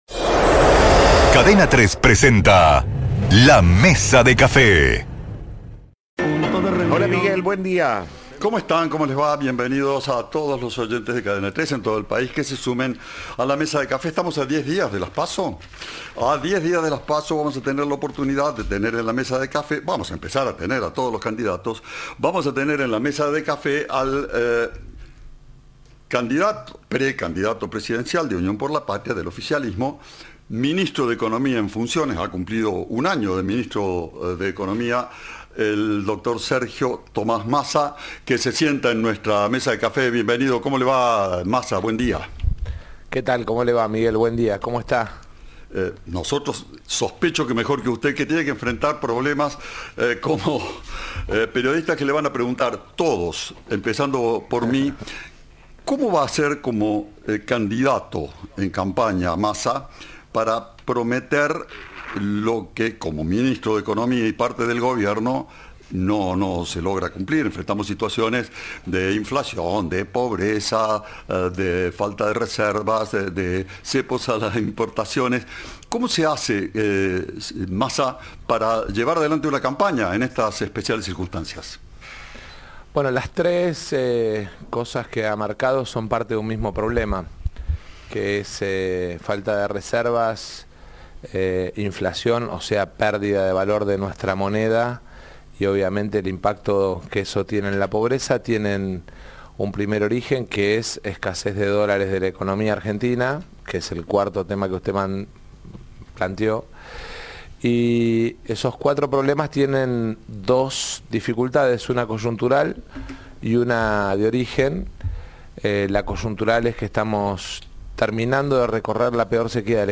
El ministro de Economía y precandidato presidencial de Unión por la Patria habló con Cadena 3 sobre su relación con la vicepresidenta. También elogió a Martín Llaryora y disparó contra Macri por la deuda con el FMI.